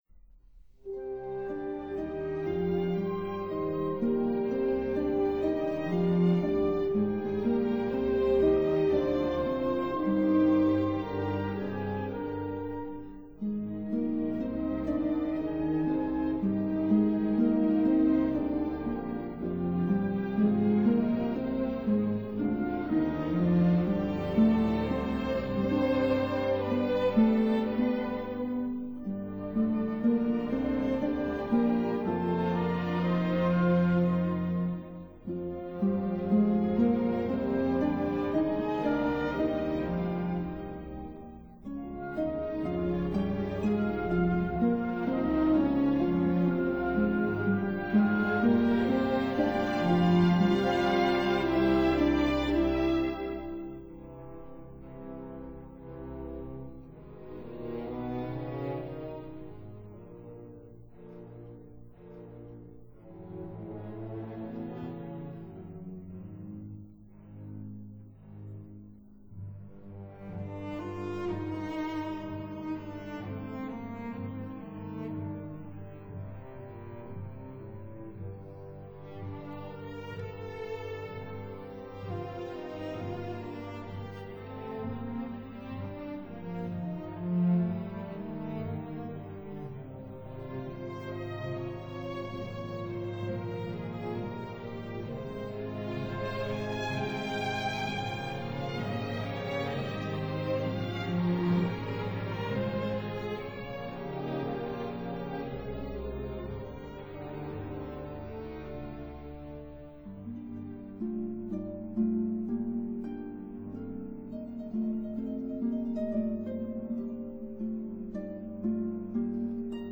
harp
bassoon
horn
trombone